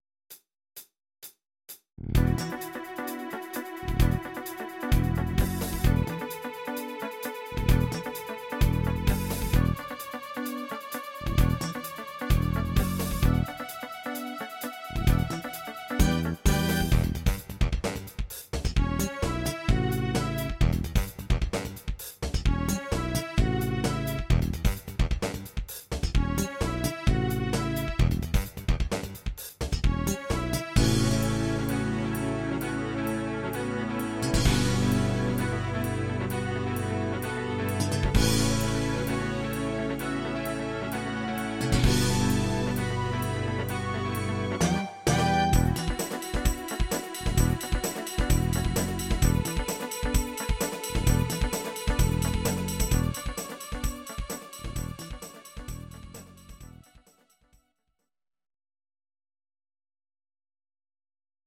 Audio Recordings based on Midi-files
Disco, Instrumental, 1970s